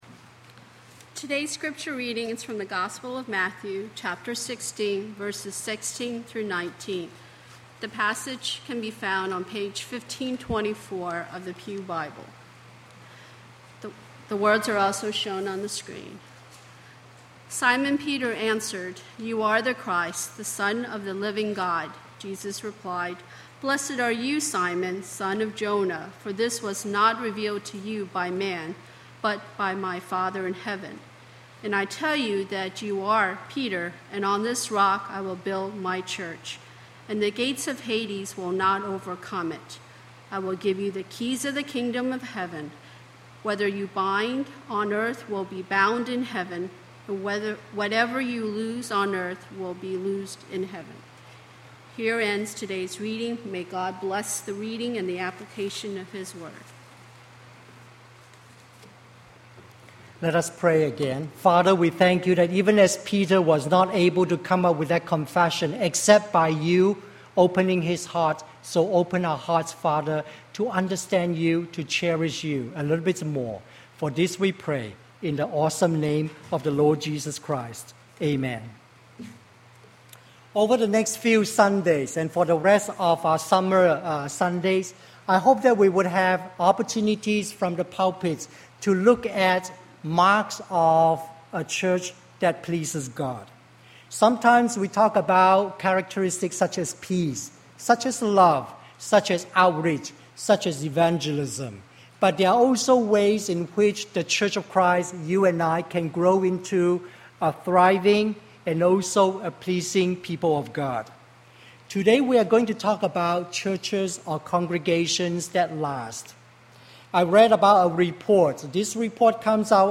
Presentation to accompany sermon